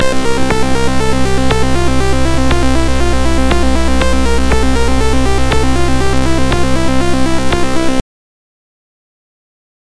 These songs are created with AutoCAD.